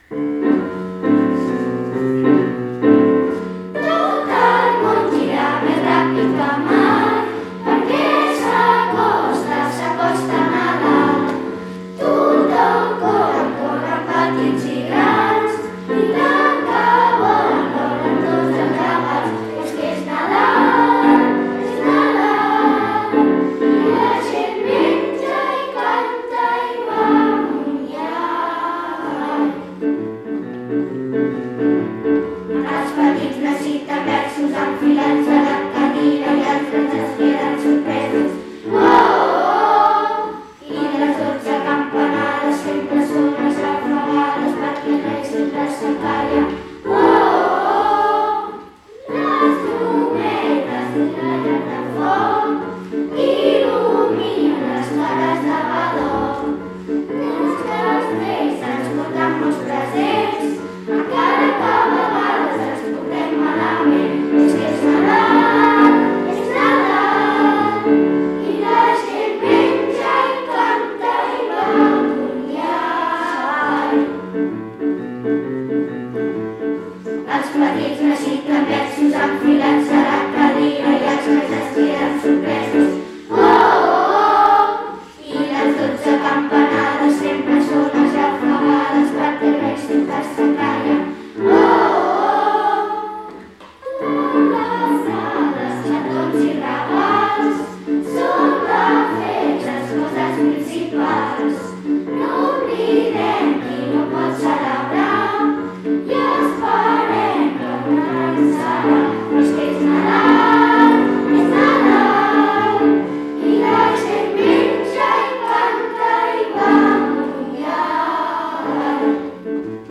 Enguany per celebrar aquestes festes a distància, hem gravat uns vídeos per a cada classe amb les seves cançons.